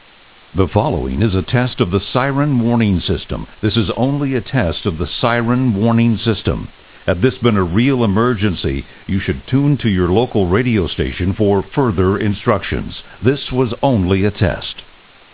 Test Message